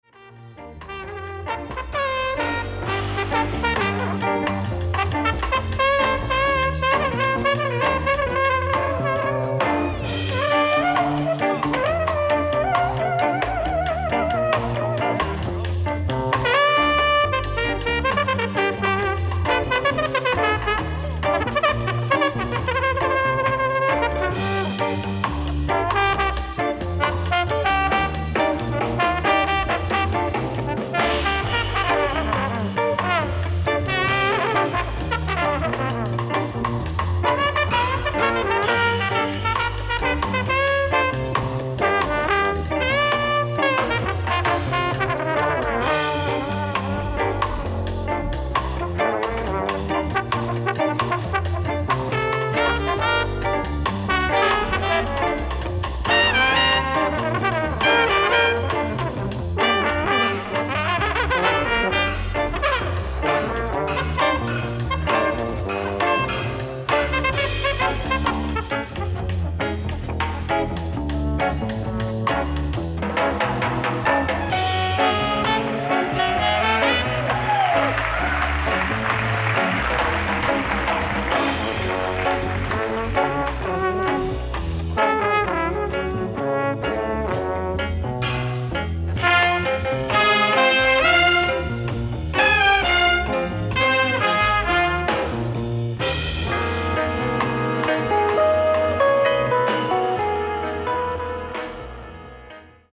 ライブ・アット。ウンブリア・ジャズ、ペルージア、イタリア 07/12/2004
※試聴用に実際より音質を落としています。